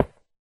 Minecraft / dig / stone2.ogg
stone2.ogg